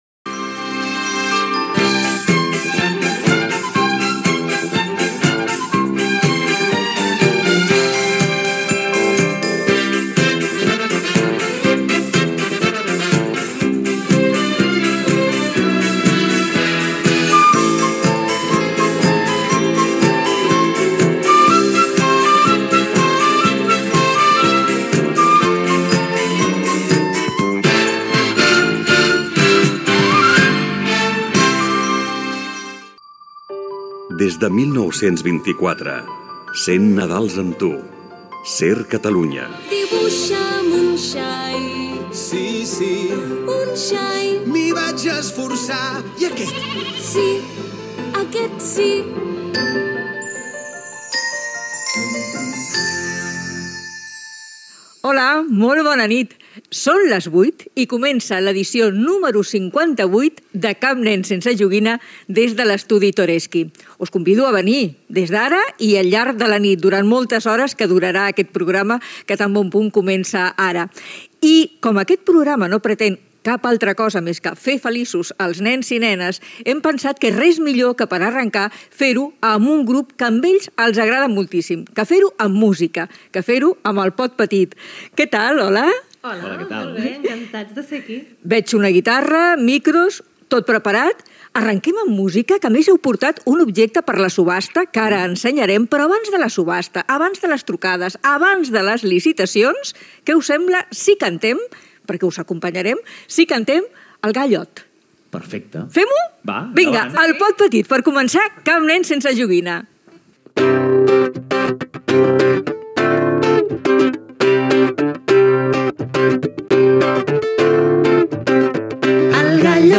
Sintonia del programa, indicatiu dels 100 anys de la ràdio, presentació de l'edició 58 del programa
entrevista a l'alcalde de Barcelona Jaume Collboni